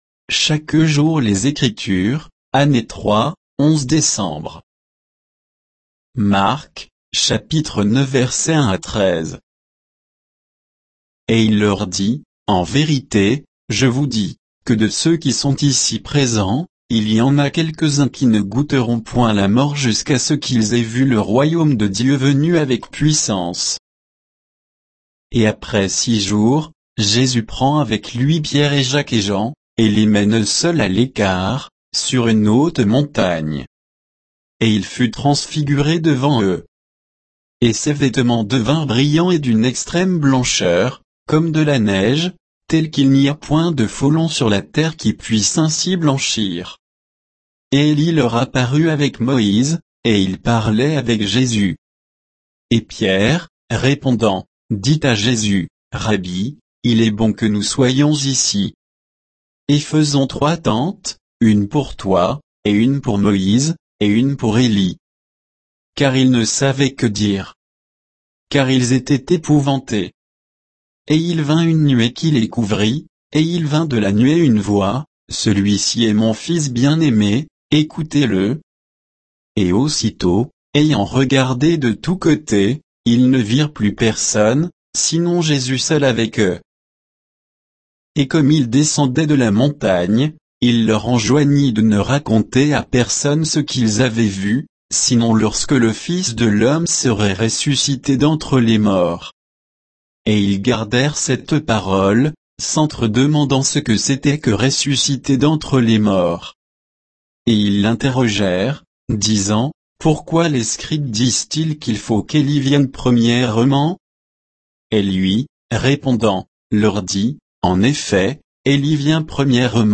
Méditation quoditienne de Chaque jour les Écritures sur Marc 9, 1 à 13